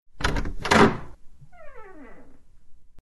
Звуки дверей
Звук открывающейся двери и тихий скрип